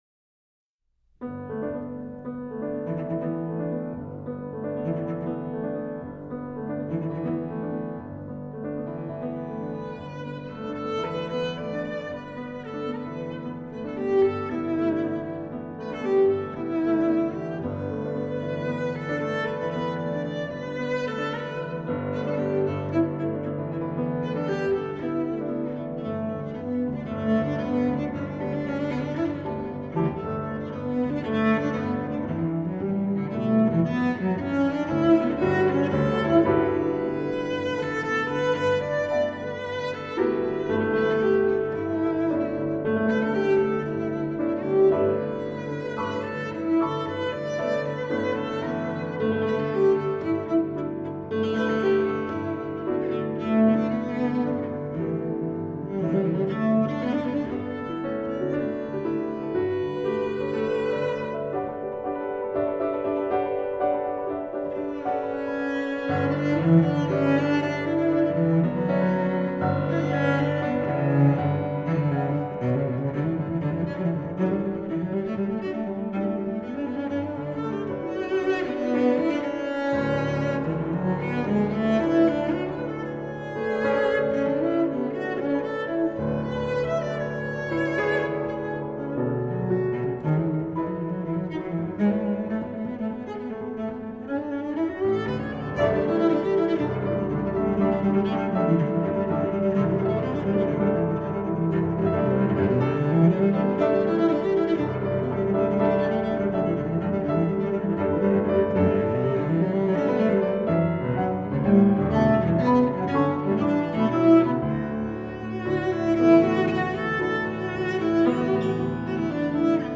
专辑语种：纯音乐专辑1CD